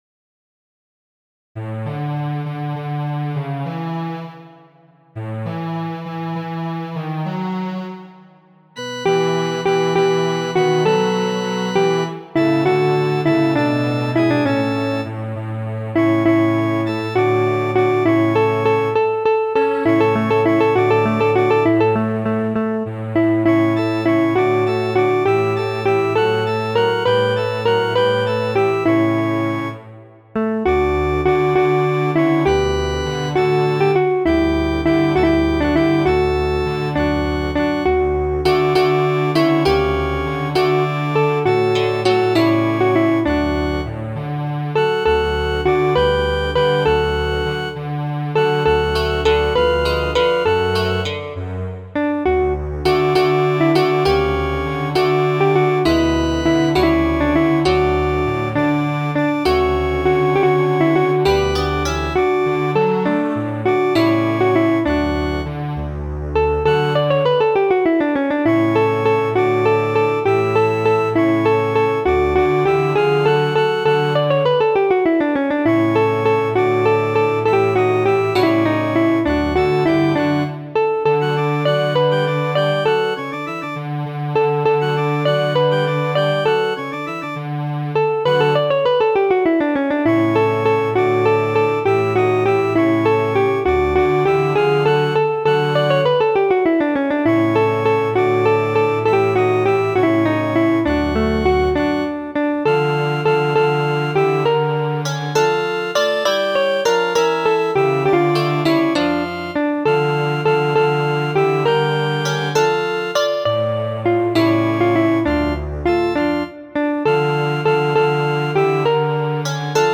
Muziko:
Variaĵoj pri fama franca kanto, komponita de Fernando Sor en 1827.